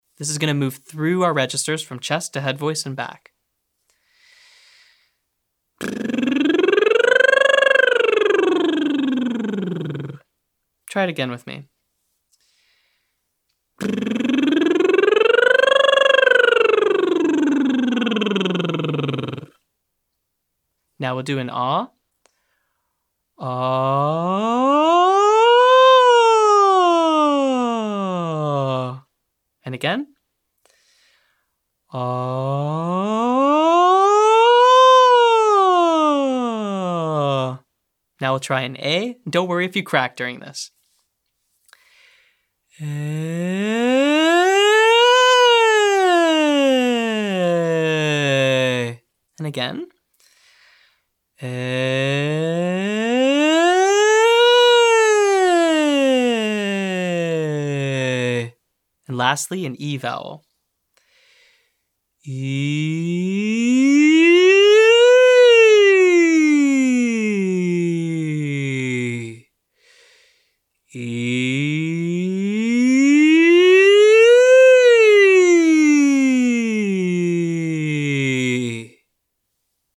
Short Daily Warmup
C) Siren Slide Lip Trill/Ah to Aye to EE (1-8-1)